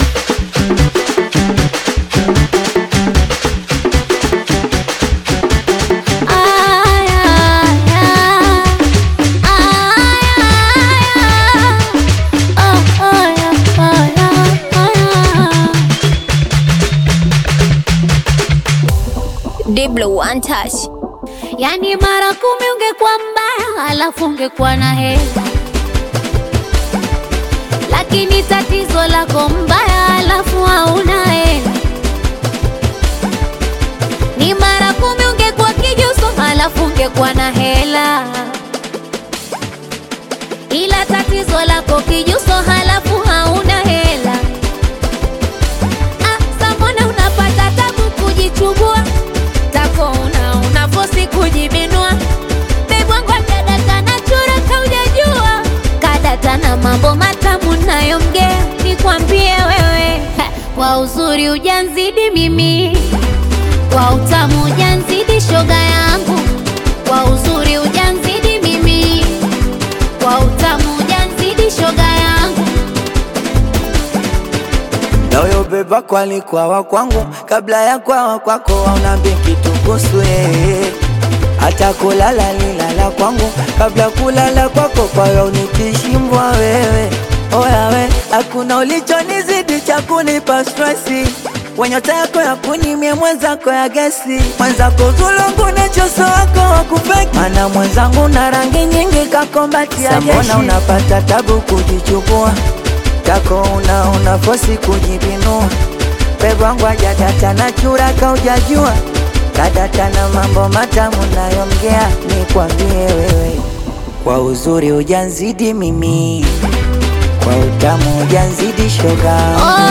Singeli You may also like